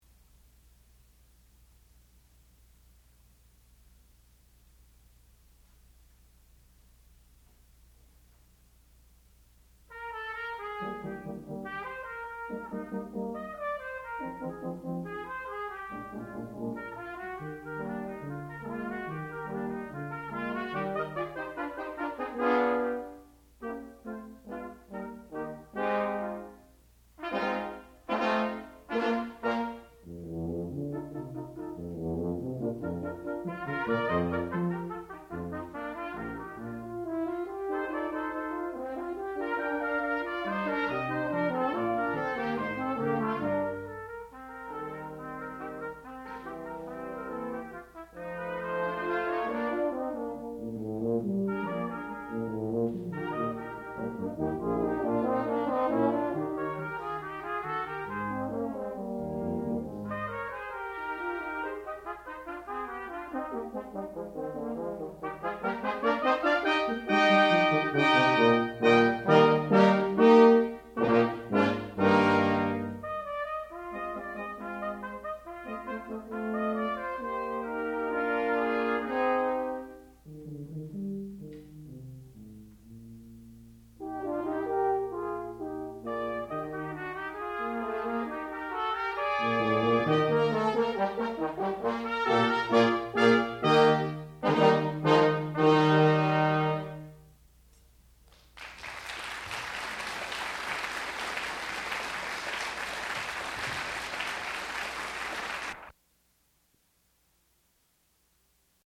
sound recording-musical
classical music
trumpet
horn